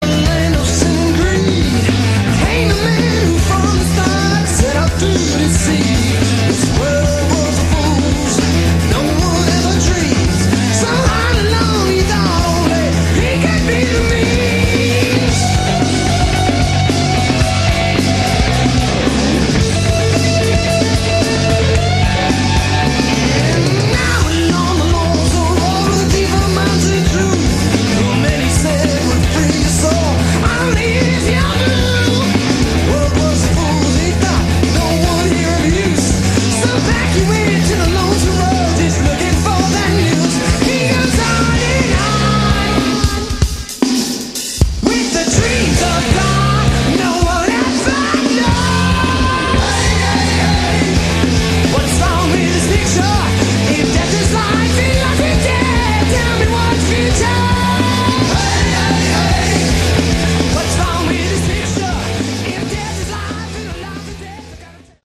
Category: Glam/Hard Rock